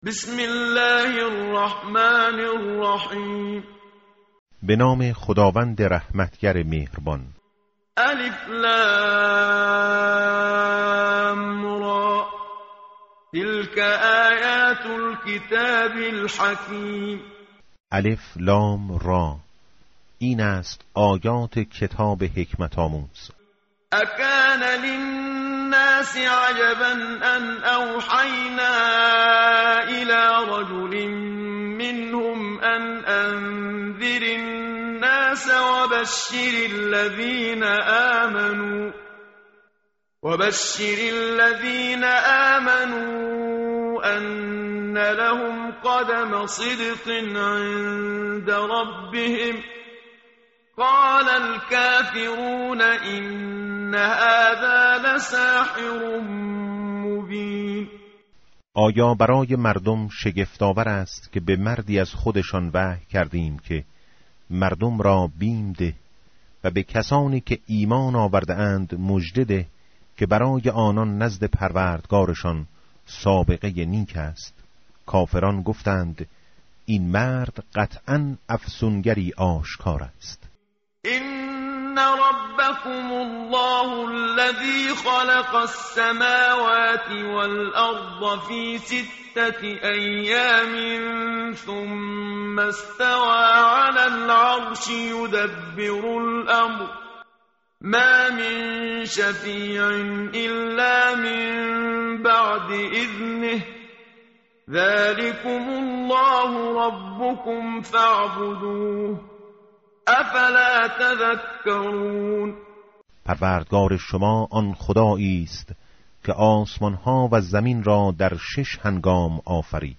tartil_menshavi va tarjome_Page_208.mp3